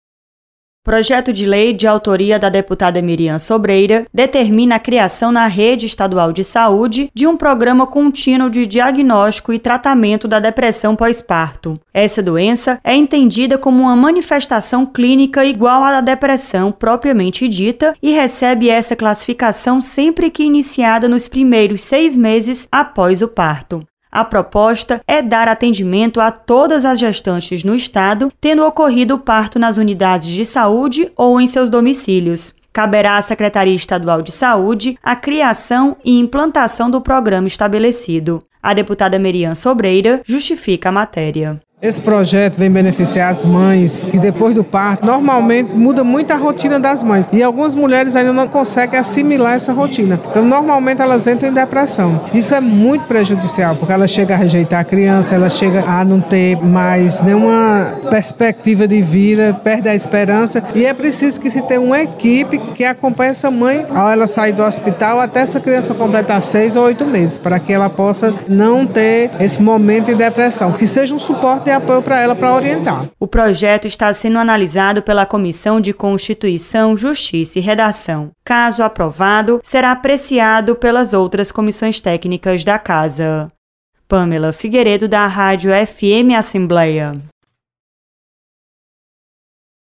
Deputada quer criação de programa para tratamento de depressão pós-parto. Repórter